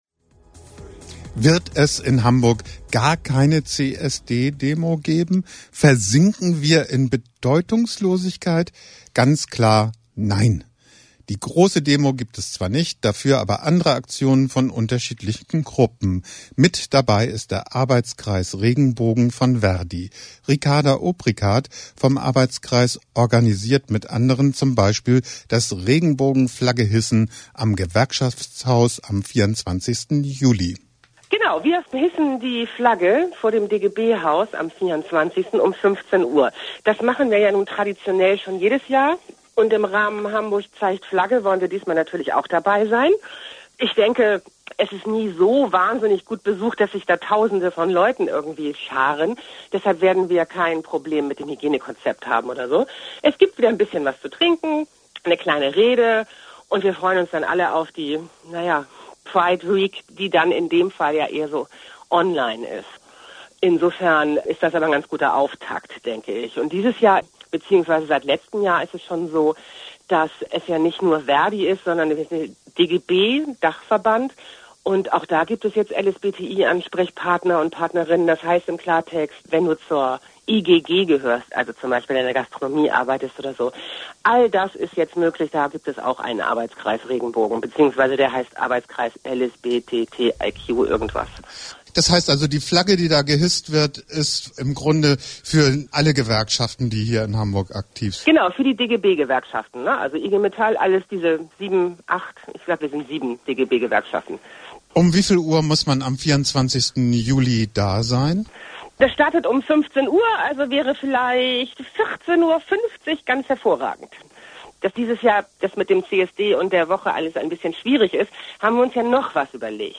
Pink Channel Nachrichten 04.07.2020